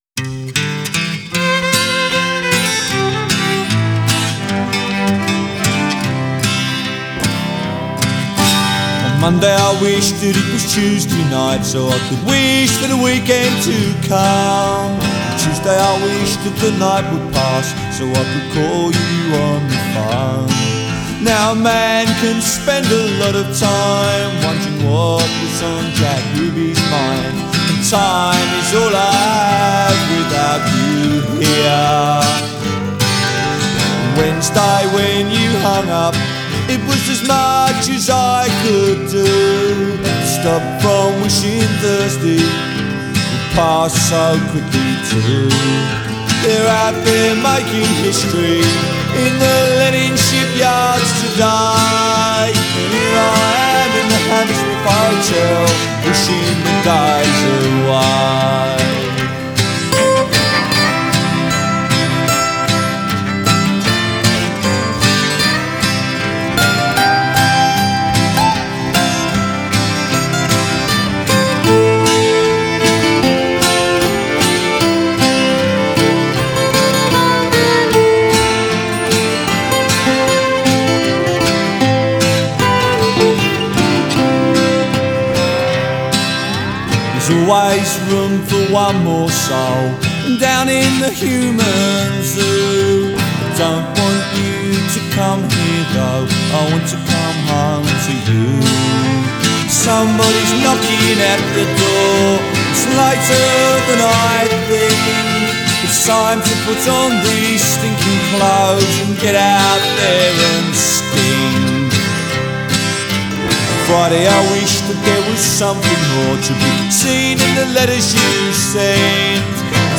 Жанр: Folk, Punk
Исполняет свои песни обычно под электрогитару.